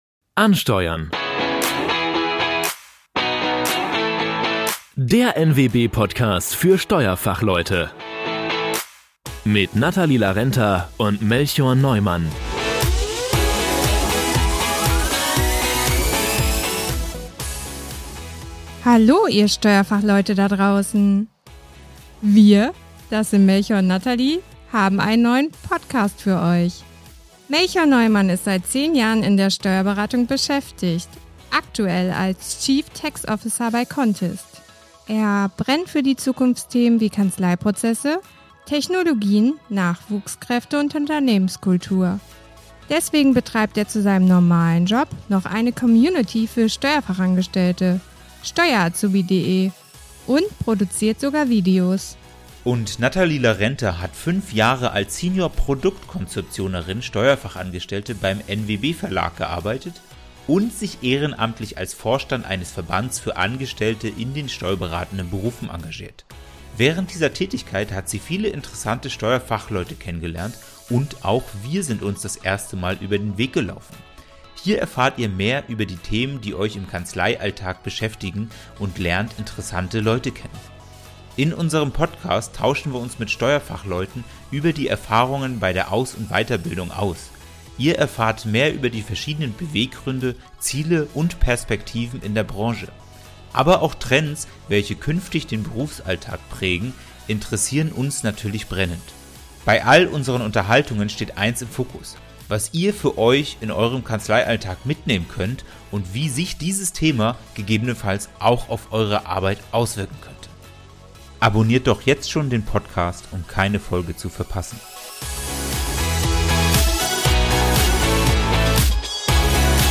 Wir tauschen uns mit Steuerfachleuten über die Erfahrungen bei der Aus- und Weiterbildung in den Steuerberufen aus, erfahren mehr über verschiedene Beweggründe, Ziele und Trends welche künftig den Berufsalltag prägen.